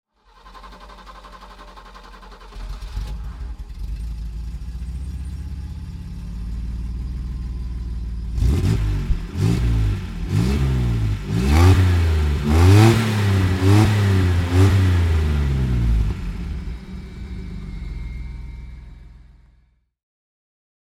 Ford Granada 2300 V6 Fastback-Limousine (1973) - Starten und Leerlauf